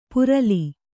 ♪ purali